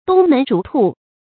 東門逐兔 注音： ㄉㄨㄙ ㄇㄣˊ ㄓㄨˊ ㄊㄨˋ 讀音讀法： 意思解釋： 見「東門黃犬」。